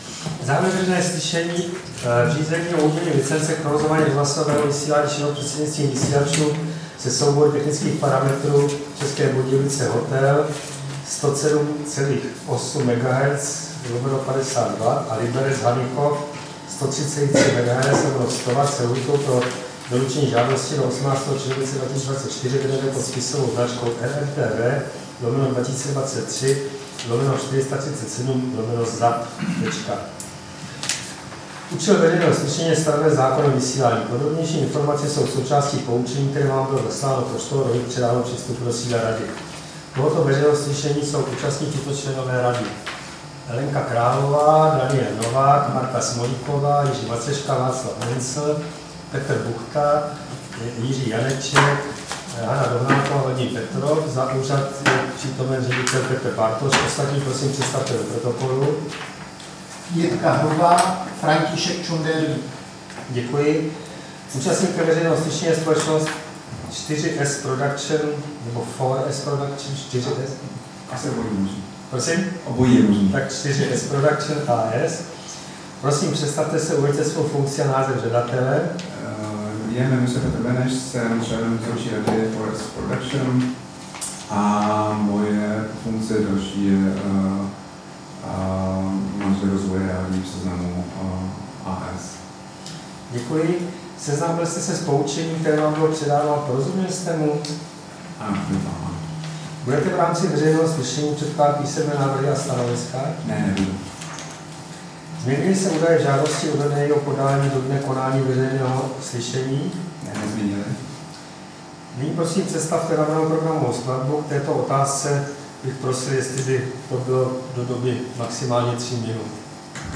Veřejné slyšení v řízení o udělení licence k provozování rozhlasového vysílání šířeného prostřednictvím vysílačů se soubory technických parametrů České Budějovice 107,8 MHz/50 W a Liberec-Hanychov 103,3 MHz/100 W
Místem konání veřejného slyšení je sídlo Rady pro rozhlasové a televizní vysílání, Škrétova 44/6, 120 00 Praha 2.